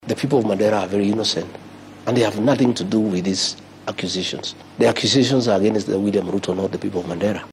Madaxweyne ku xigeenkii hore ee dalka Rigathi Gachagua oo xalay waraysi gaar ah siinayay telefishanka maxaliga ah ee Citizen ayaa beeniyay eedeyn uga timid madaxda Mandera.